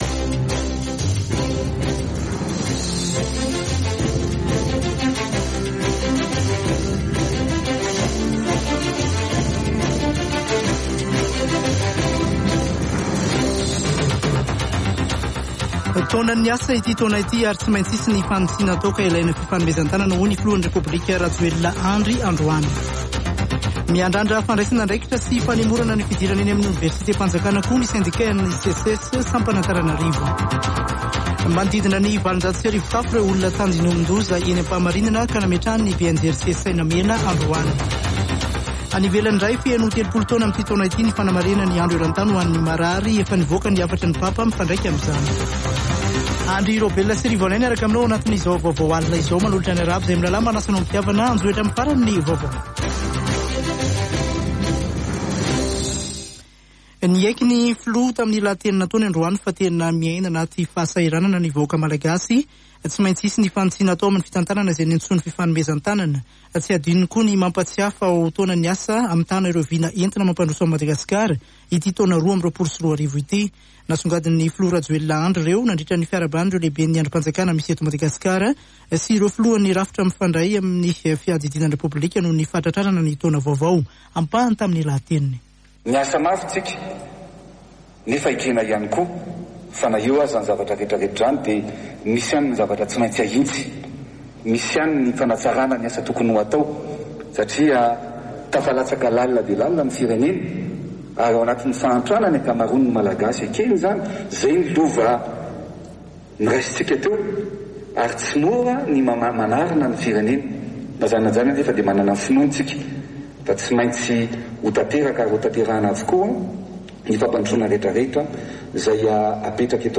[Vaovao hariva] Alarobia 5 janoary 2022